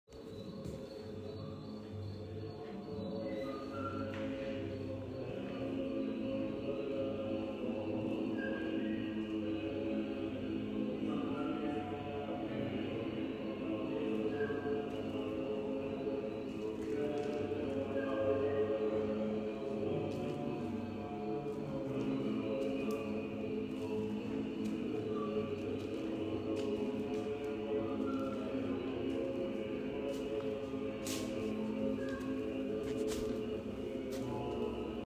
en This Sound is a live sound recording done while visiting the exhibition about collapse "before silence" later we used this sounds to improvise on it and create ambience
en Voiceeffects
en Echo
en Reverb
en Porakishvili Museum, Tbilisi, Georgia